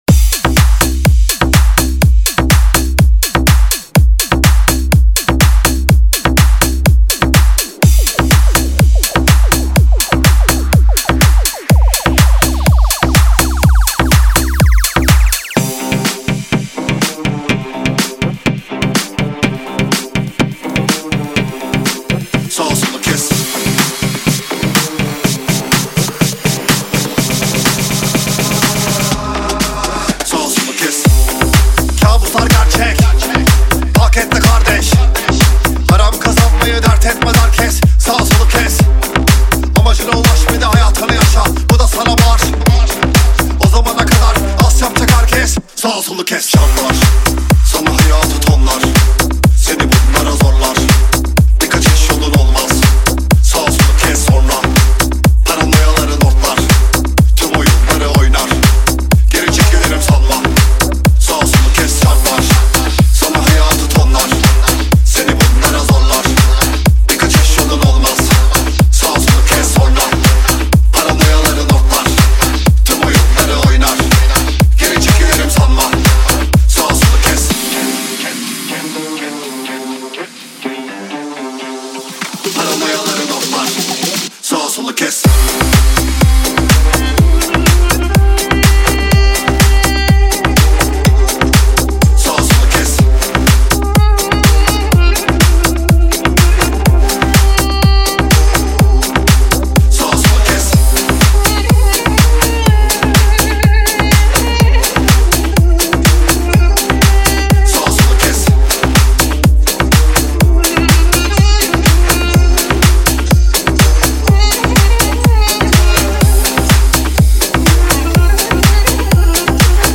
رپ بیس دار ترکی